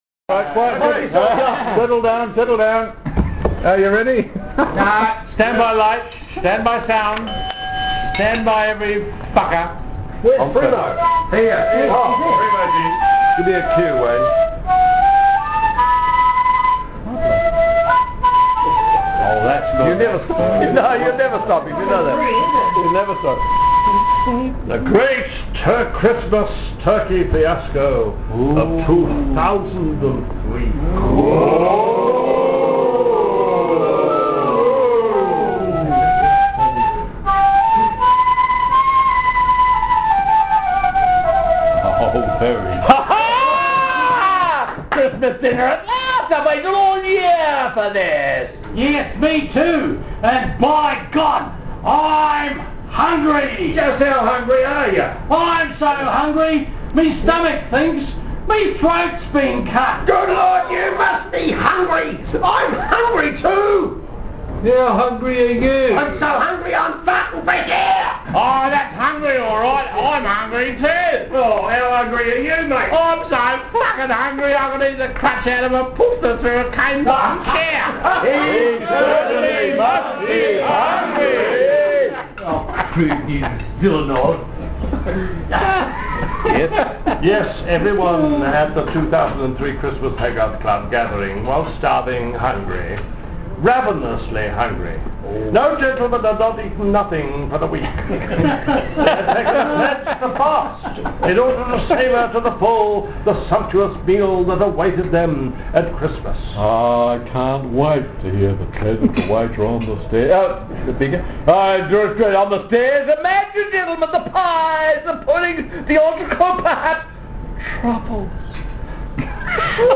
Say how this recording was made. The second was "The Great Christmas Turkey Fiasco", performed without rehearsal at the Christmas luncheon of 2003.